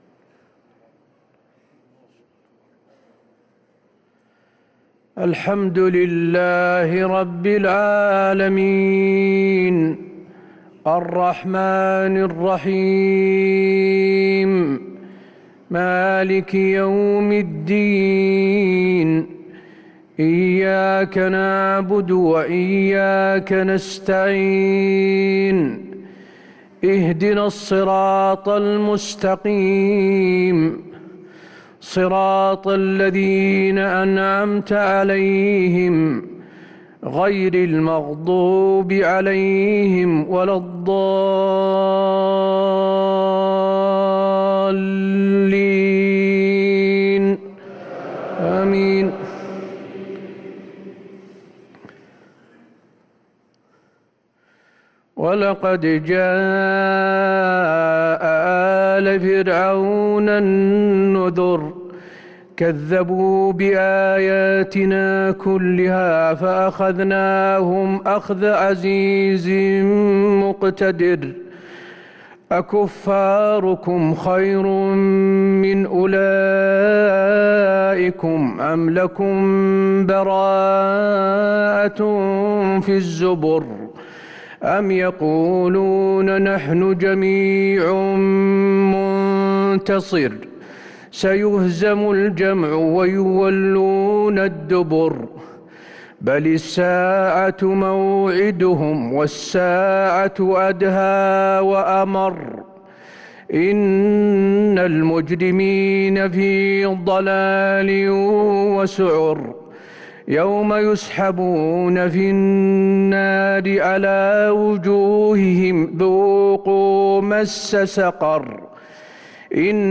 صلاة العشاء للقارئ حسين آل الشيخ 14 رمضان 1444 هـ
تِلَاوَات الْحَرَمَيْن .